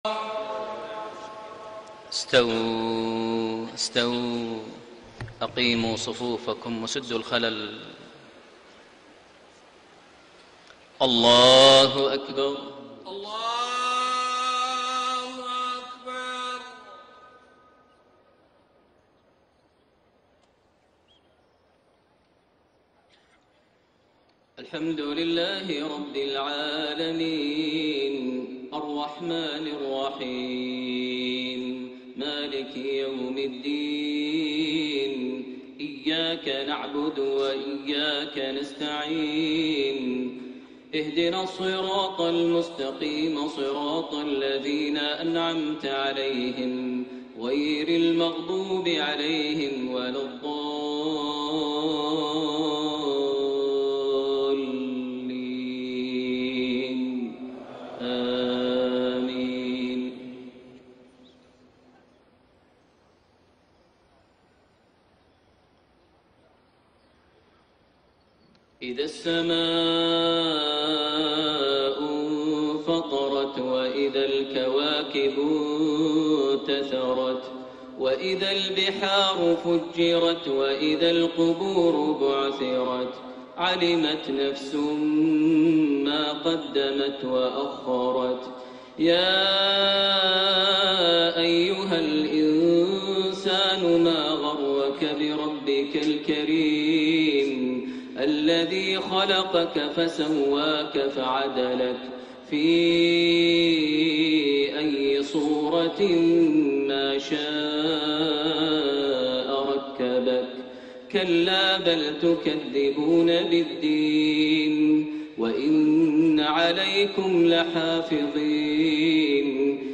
صلاة المغرب 20 جمادى الآخرة 1433هـ سورتي الانفطار و القارعة > 1433 هـ > الفروض - تلاوات ماهر المعيقلي